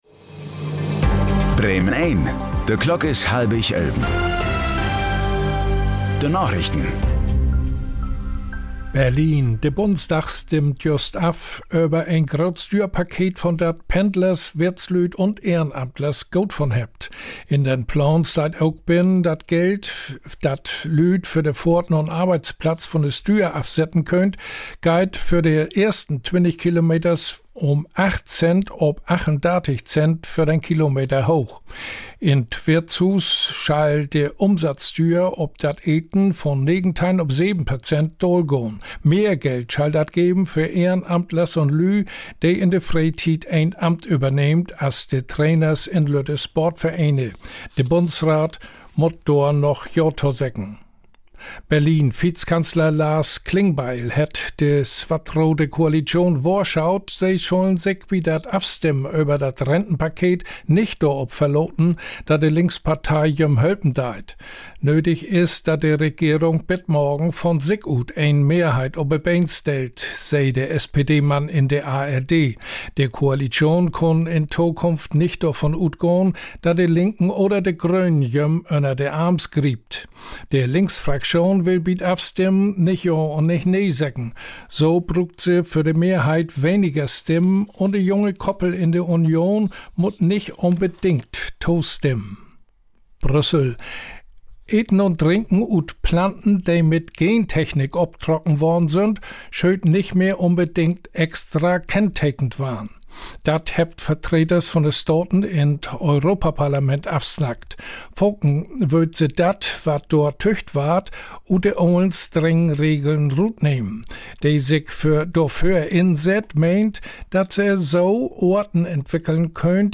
Plattdüütsche Narichten vun'n 4. Dezember 2025